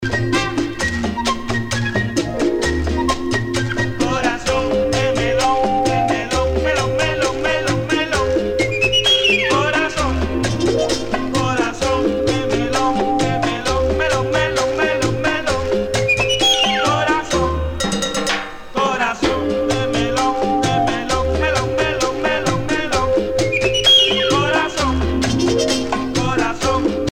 danse : cha cha cha